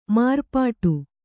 ♪ mārpāṭu